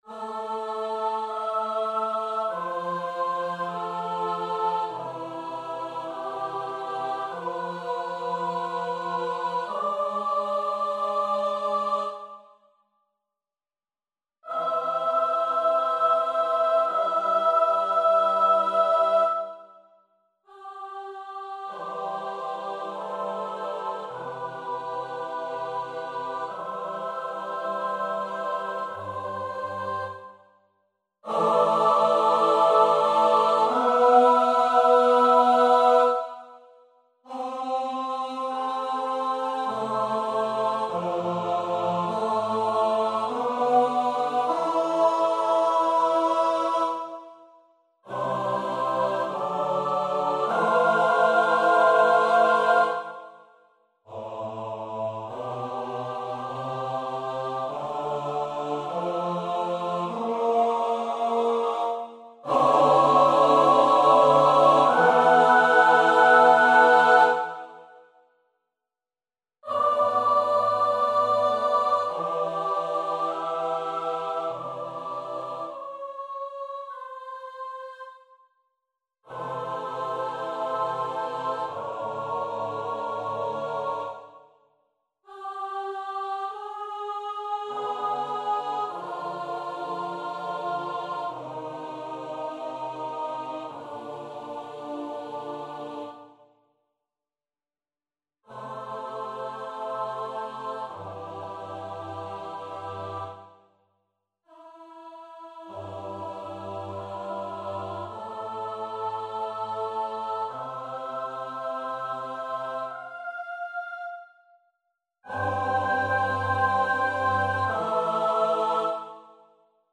Title: Kringloop Composer: Marijke de Kler Lyricist: Johann Geiler von Kaysersberg / Marijke de Klercreate page Number of voices: 4vv Voicing: SATB Genre: Sacred, Motet
Language: Dutch Instruments: A cappella